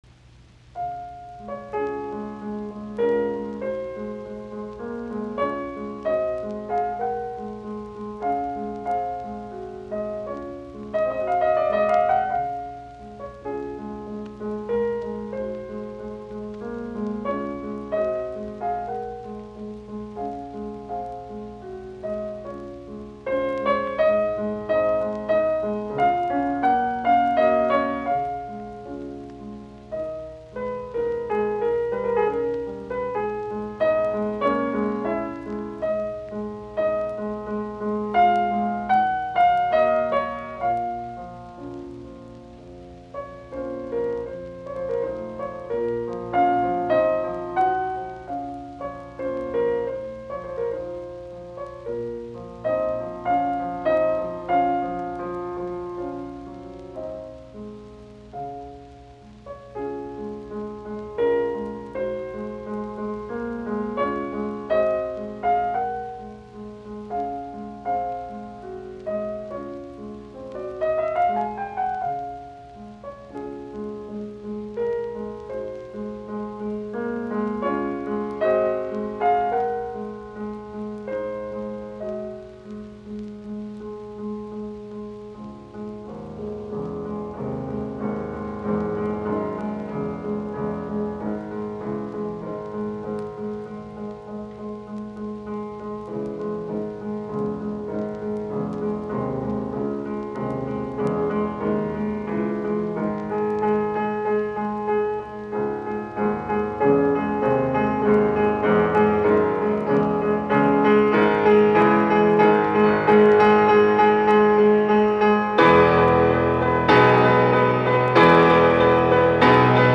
in D-flat major, Sostenuto